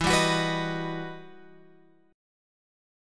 E Minor Major 9th